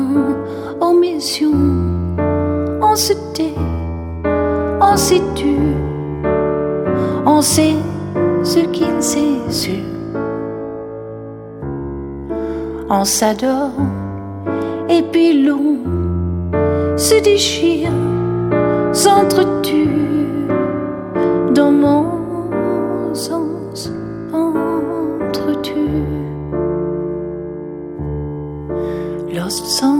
"templateExpression" => "Chanson francophone"